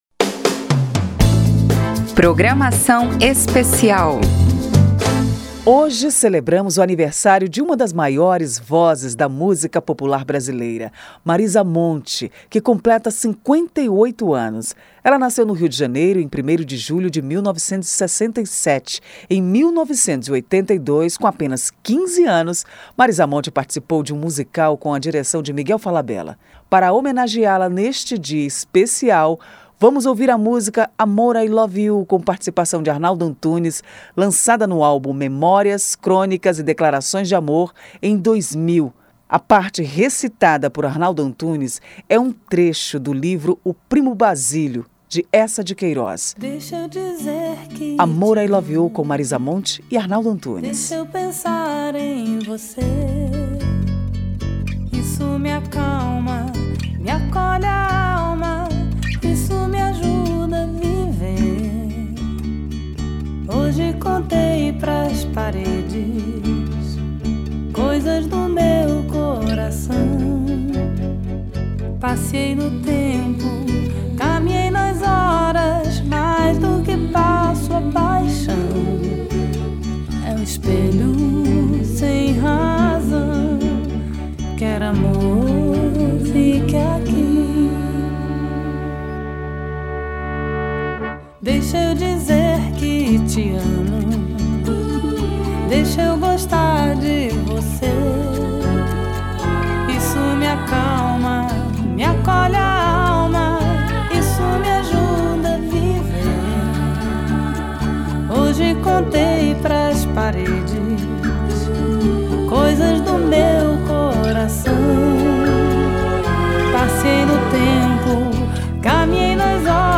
E, para comemorar o aniversário da carioca, nascida no dia  primeiro de julho de 1967, a Rádio Câmara preparou uma programação especial com seis blocos com informações e músicas.